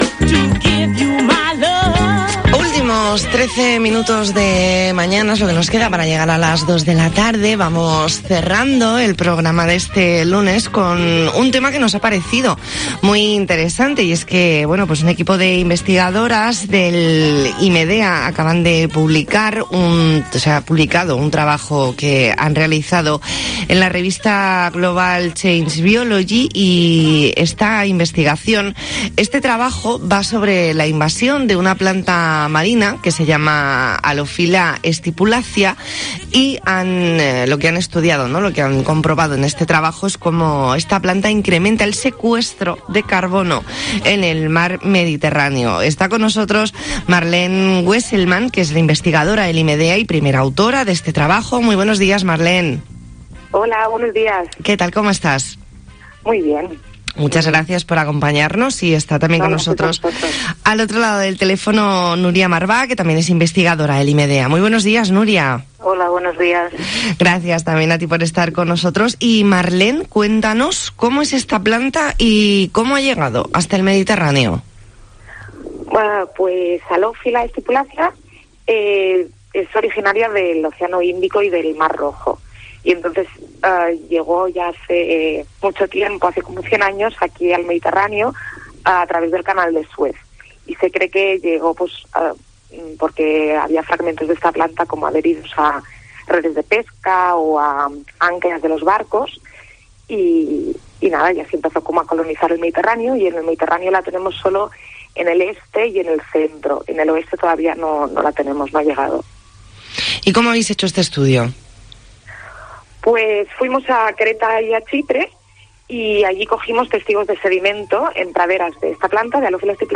Entrevista en La Mañana en COPE Más Mallorca, lunes 12 de abril de 2021.